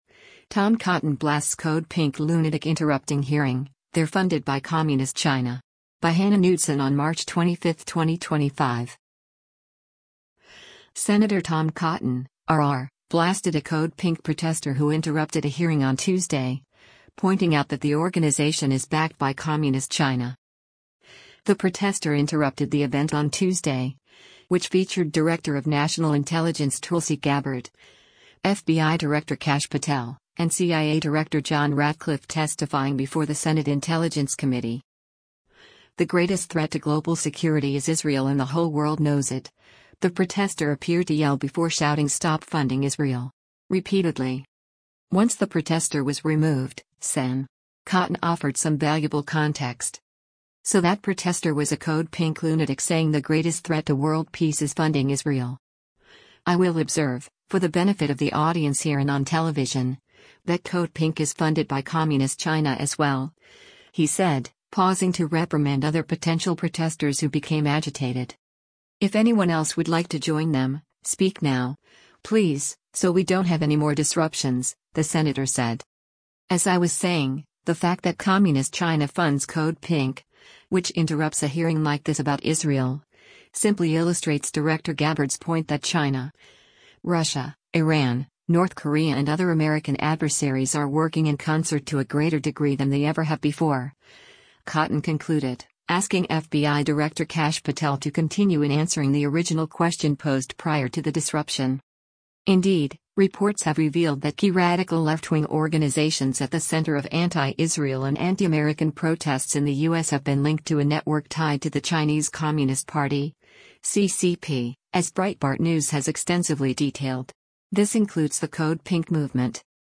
Sen. Tom Cotton (R-AR) blasted a Code Pink protester who interrupted a hearing on Tuesday, pointing out that the organization is backed by Communist China.
“The greatest threat to global security is Israel and the whole world knows it,” the protester appeared to yell before shouting “Stop funding Israel!” repeatedly.